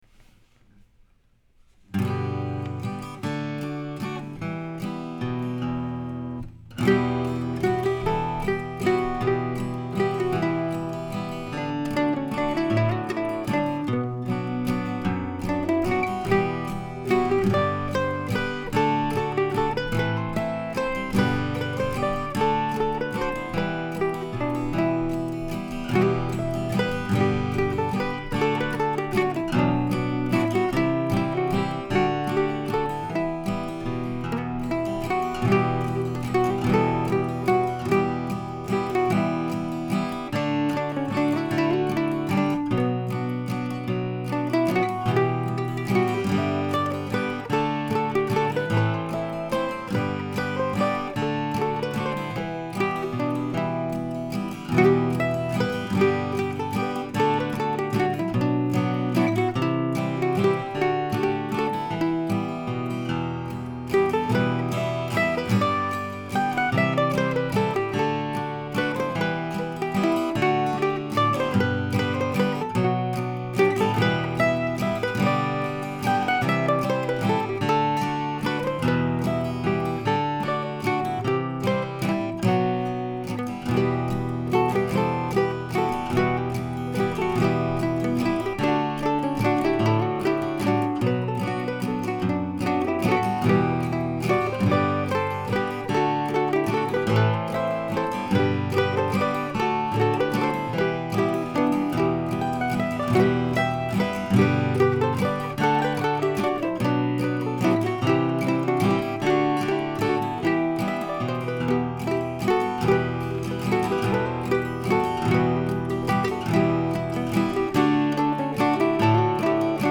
I remember as I was writing the middle section that features this tune thinking that it would be a fine, stand alone waltz.
That note popped up again in my aging memory this week and I finally retrieved the tune and turned it into a simple dance piece with the title "Harpers Ferry Station."
E minor